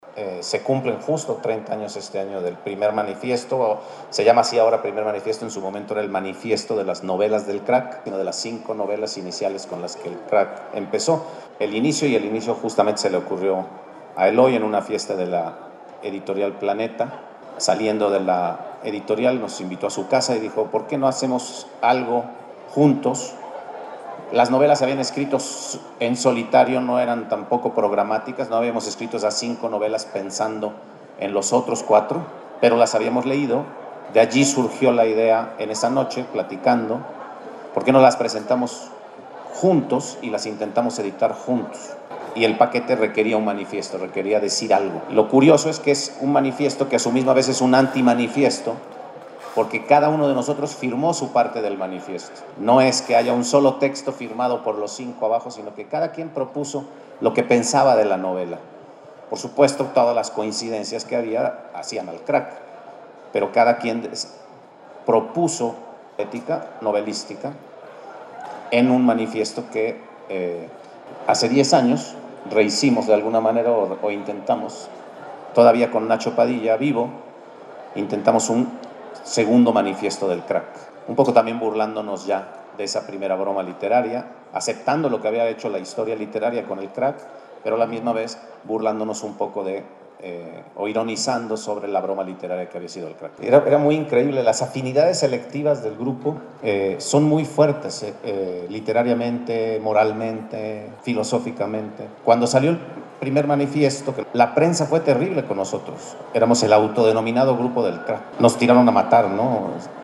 Jorge Volpi, Pedro Ángel Palou y Eloy Urroz, fundadores del movimiento literario la “Generación del Crack”, conversaron en el Foro Carolino dentro de la FENALI BUAP 2026.
FENALI_Pedro-Angel-Palou_Crack.mp3